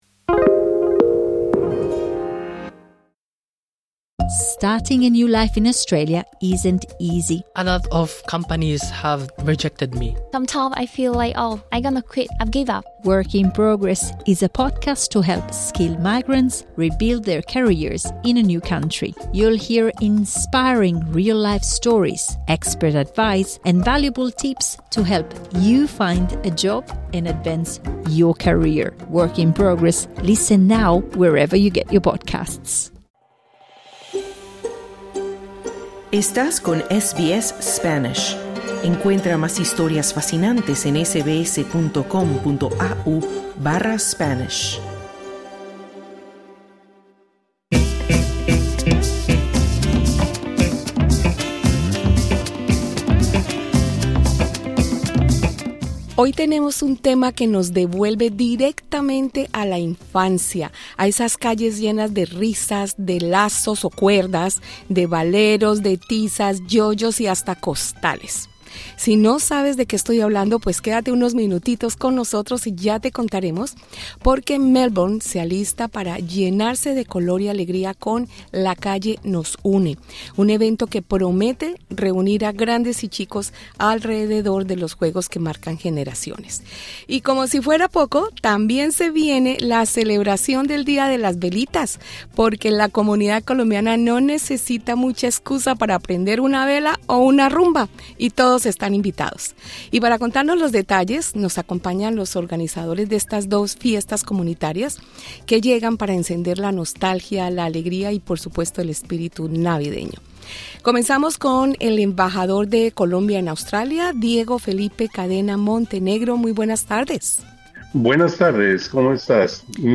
Entrevista con el embajador de Colombia en Australia, Diego Felipe Cadena Montenegro